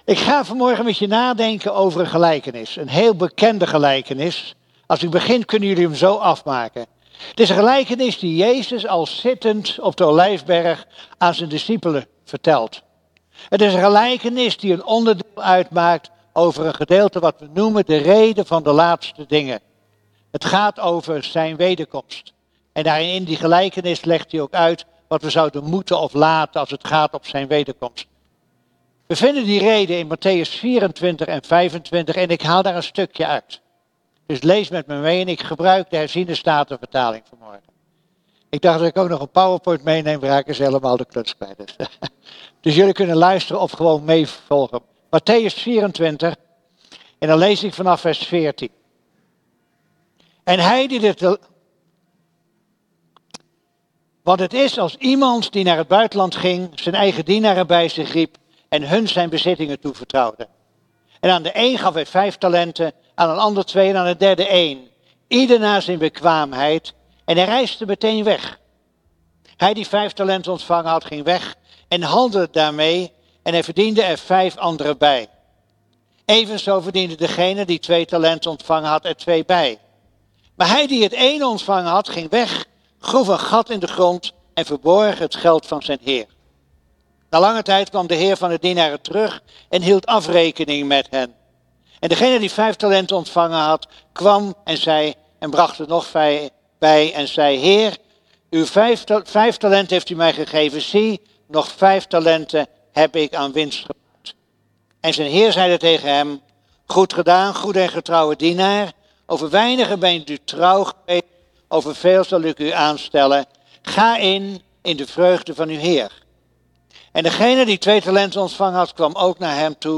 De preek